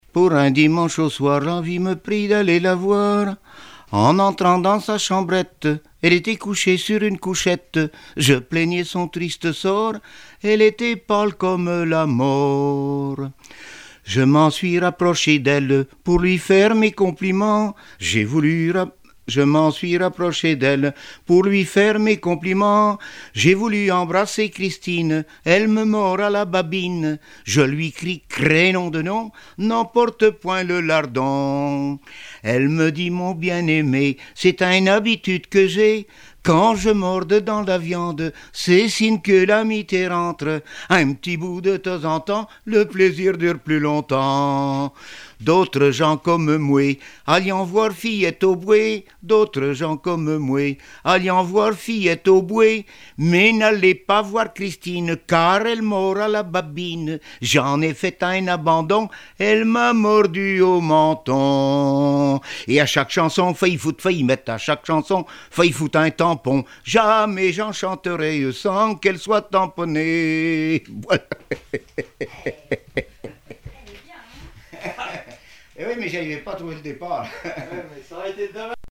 Mémoires et Patrimoines vivants - RaddO est une base de données d'archives iconographiques et sonores.
Genre laisse
Répertoire de chansons populaires et traditionnelles
Pièce musicale inédite